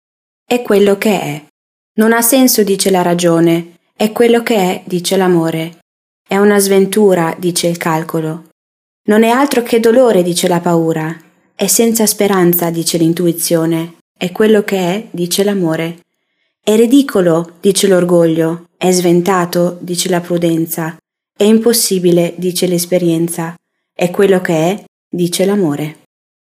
Spezialistin für weltliche Trauungen
Als Weltenbummlerin zähle ich Italien, Deutschland und Süd Afrika als meine Heimat und ermögliche so akzentfreie Trauungen auf Deutsch, Italienisch und Englisch.